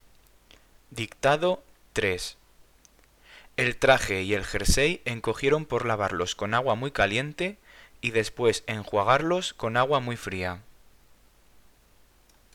Dictado